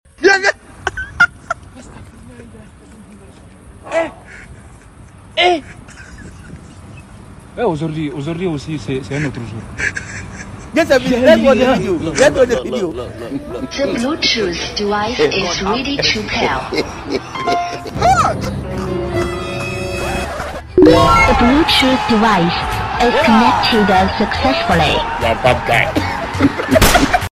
Don’t give up 😂😂 tapping sound effects free download